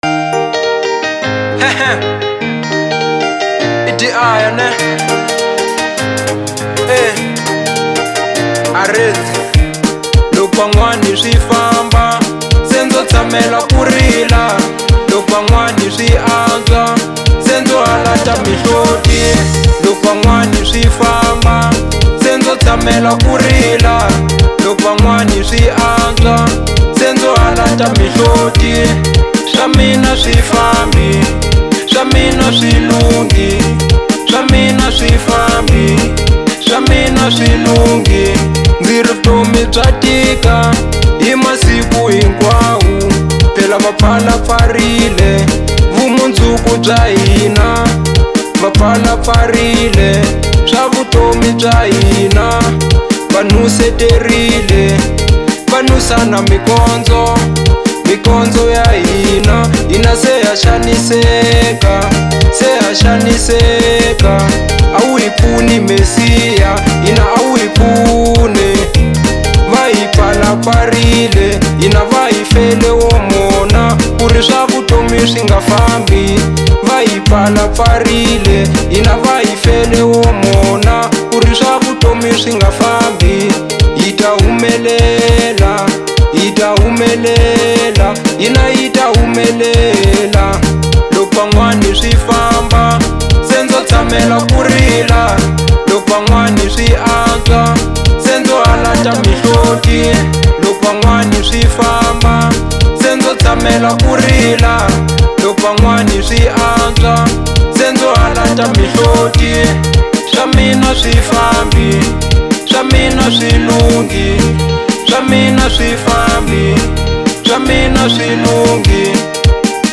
03:57 Genre : Marrabenta Size